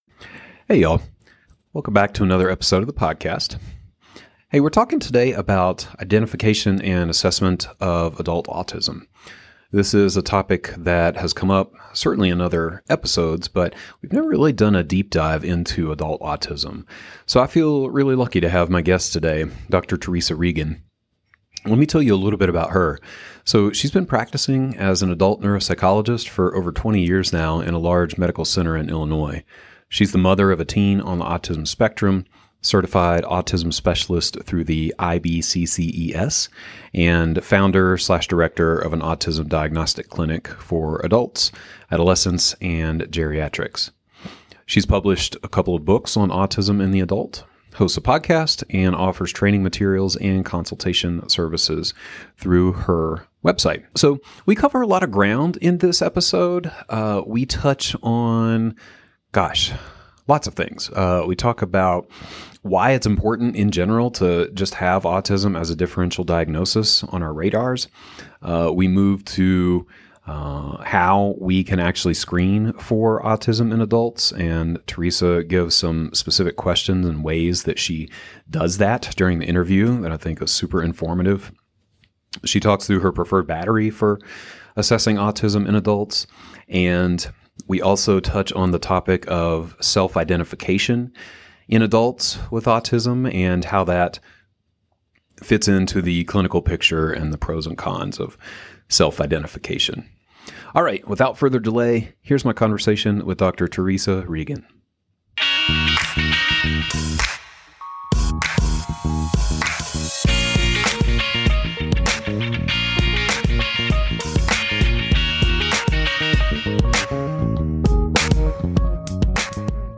Recorded audio with transcript.